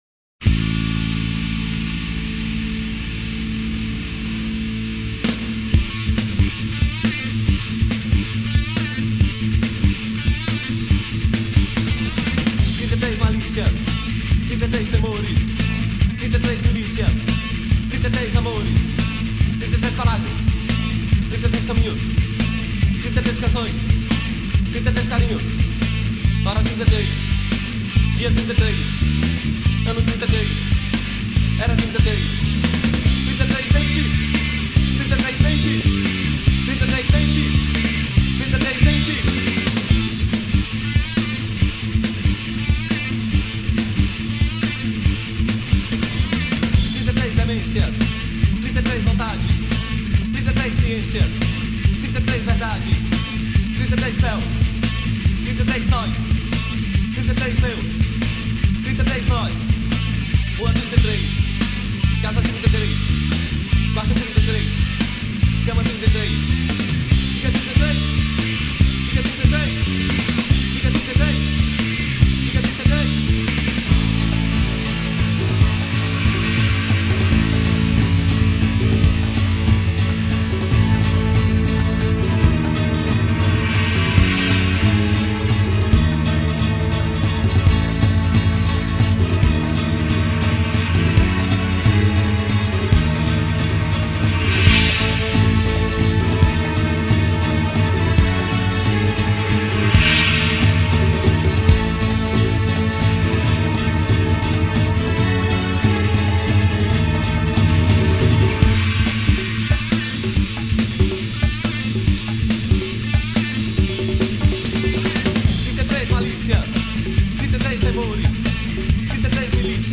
1040   03:53:00   Faixa:     Rock Nacional
Baixo Elétrico 6
Teclados
Percussão